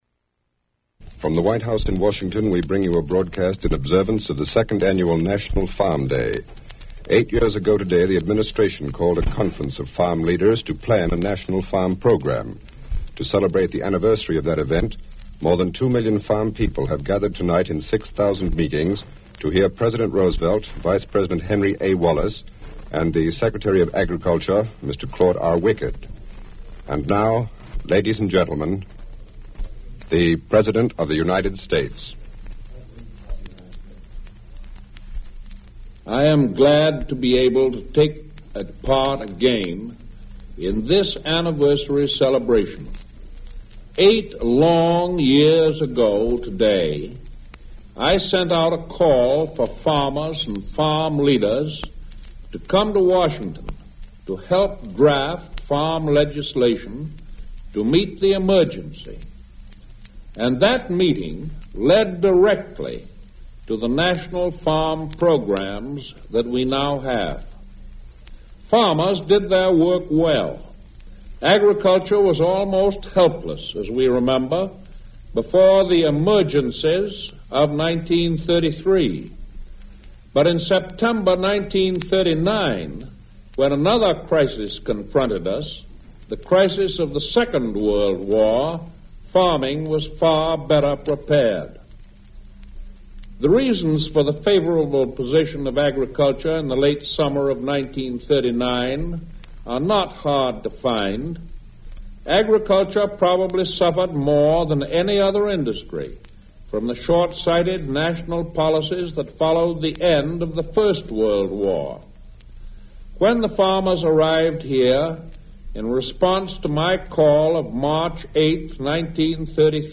U.S. President Franklin Roosevelt remarks at second annual National Farm Day